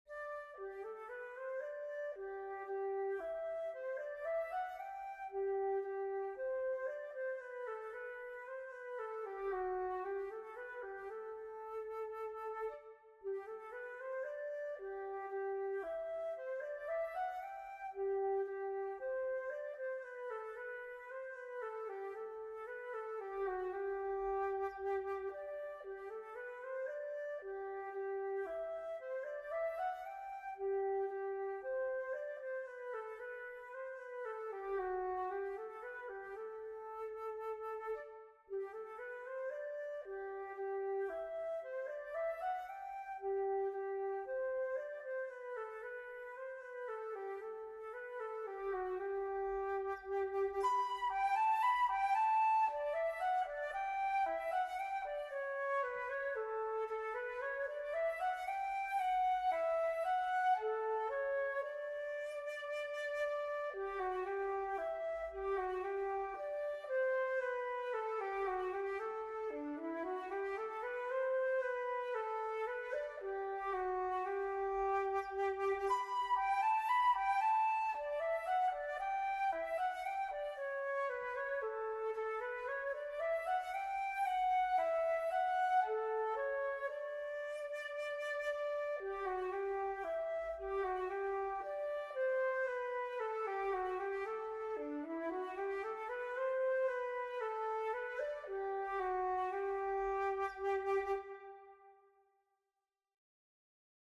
This arrangement is for solo flute.